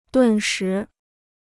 顿时 (dùn shí) Free Chinese Dictionary